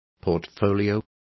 Complete with pronunciation of the translation of portfolio.